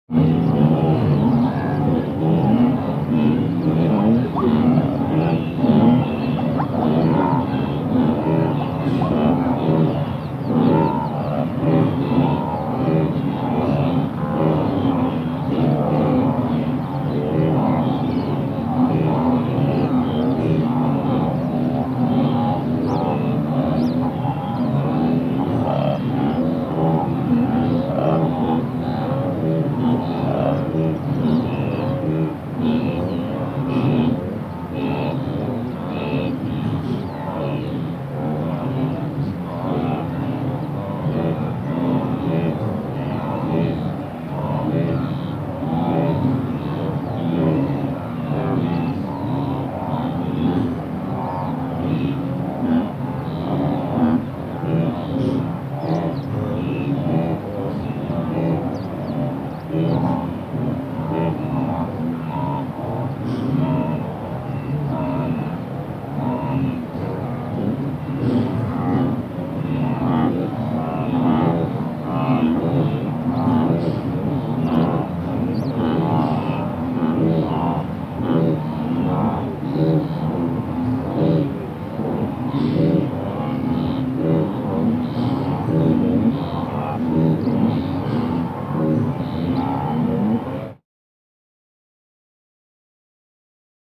دانلود صدای گاو وحشی 7 از ساعد نیوز با لینک مستقیم و کیفیت بالا
جلوه های صوتی